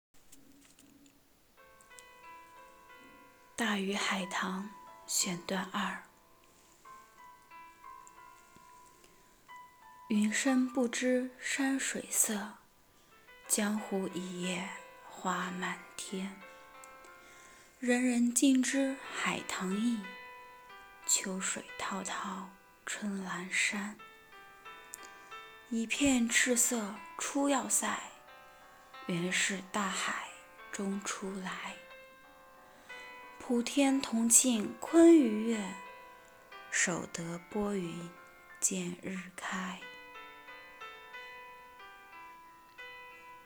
“阅读的力量--读给你听”主题朗诵